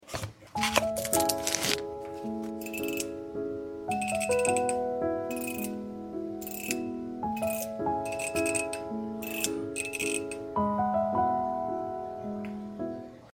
The Most Pleasing Fidget Slider